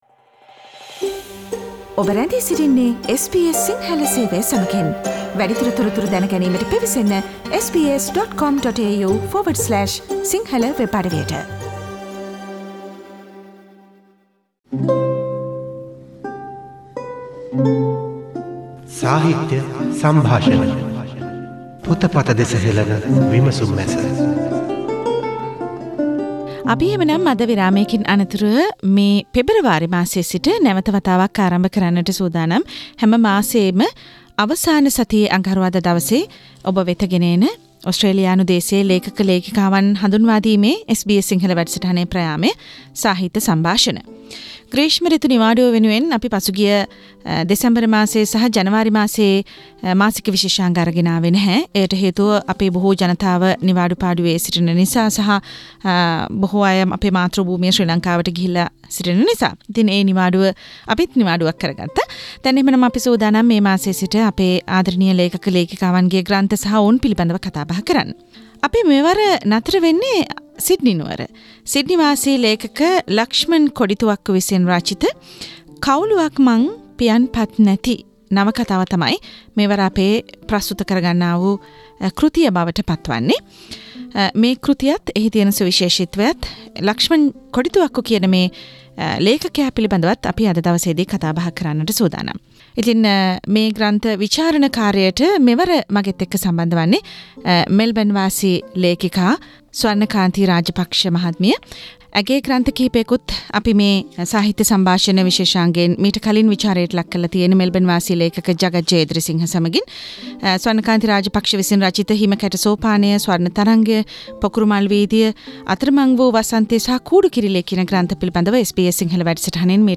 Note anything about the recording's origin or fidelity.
SBS Sinhala Monthly book review Source: SBS Sinhala radio